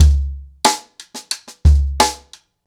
Wireless-90BPM.1.wav